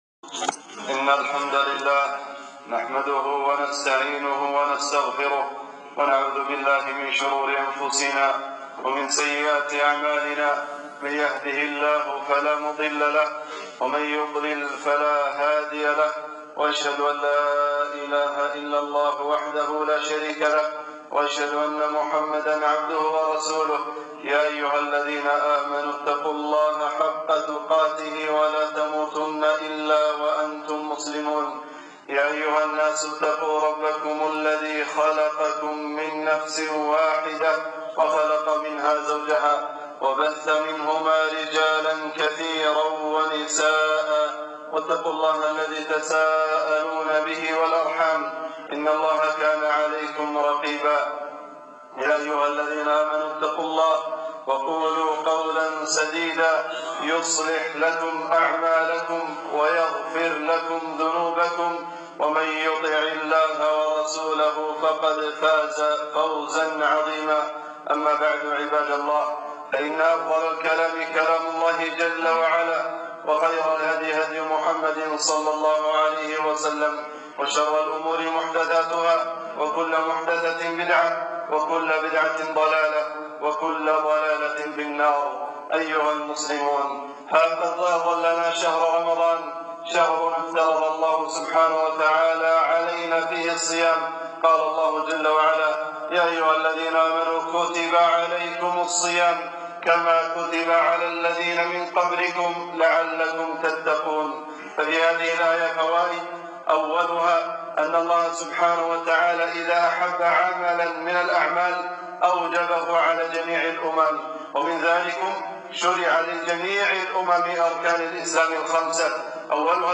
خطبة - رمضان فضائل و أعمال 5-9-1440 هــ